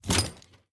ui_interface_250.wav